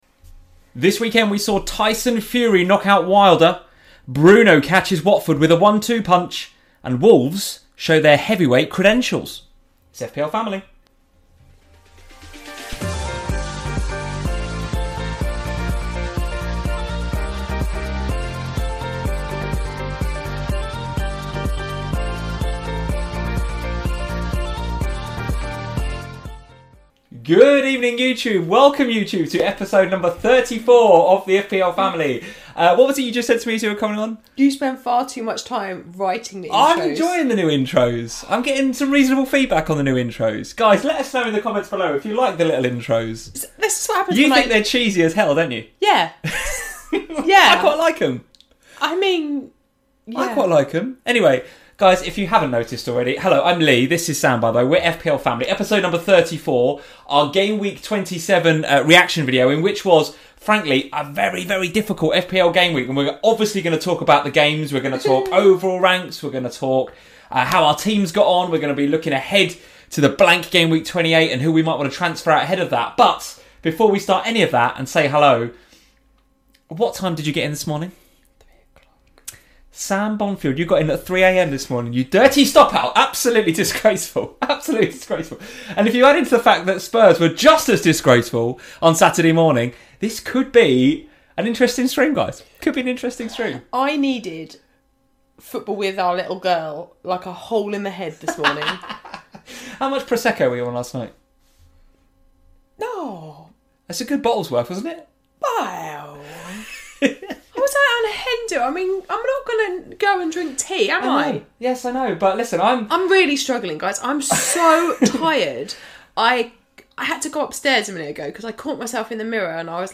- FPL GAMEWEEK 27 - FPL Family Season 3, Episode 34, Feb 23, 2020, 10:03 PM Headliner Embed Embed code See more options Share Facebook X Subscribe Welcome to FPL Family, a chat show dedicated to all things Fantasy Premier League.